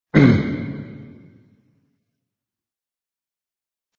Descarga de Sonidos mp3 Gratis: grito 25.